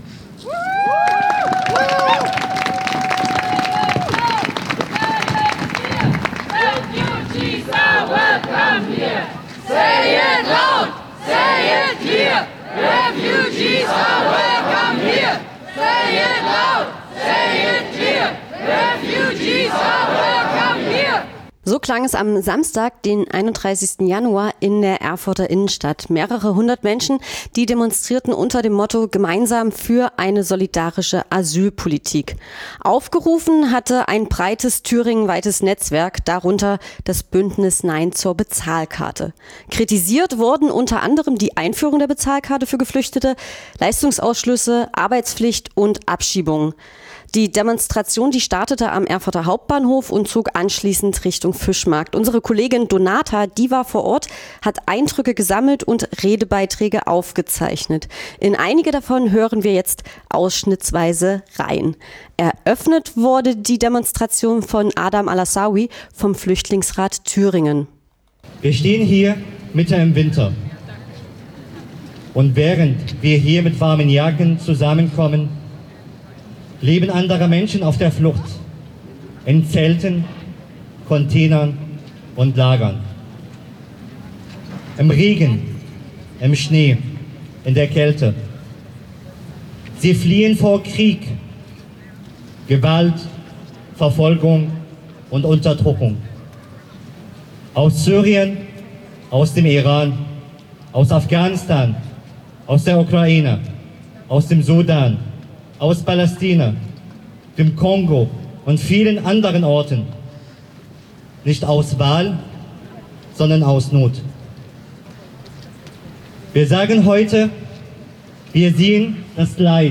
Radio F.R.E.I. war vor Ort, hat Eindr�cke gesammelt und Redebeitr�ge aufgezeichnet.